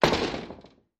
Door Slams, Small, Rattley Door.